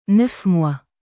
neuf moisヌァフ モワ